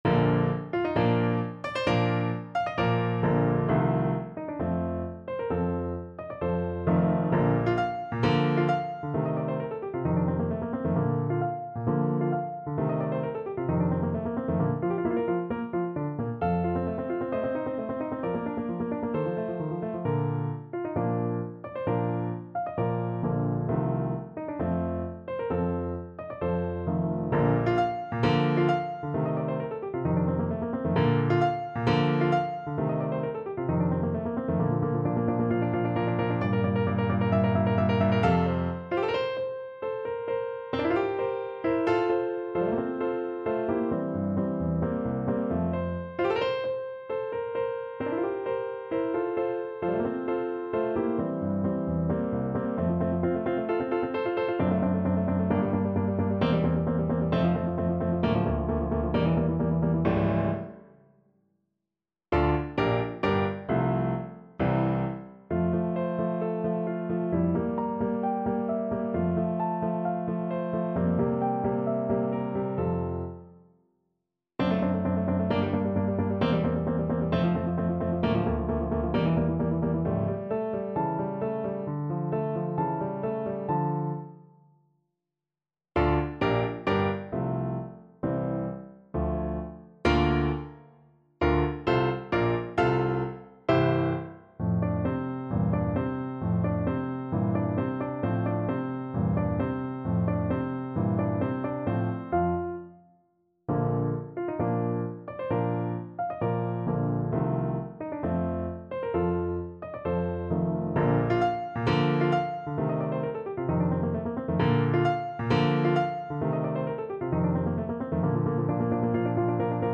~ = 132 Allegro (View more music marked Allegro)
4/4 (View more 4/4 Music)
Classical (View more Classical Trumpet Music)